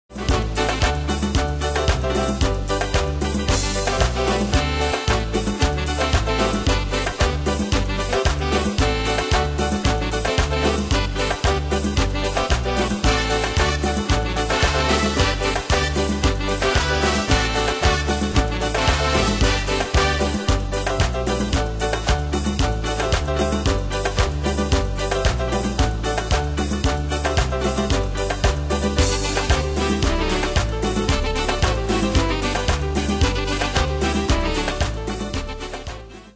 Style: Latino Ane/Year: 1996 Tempo: 113 Durée/Time: 4.59
Pro Backing Tracks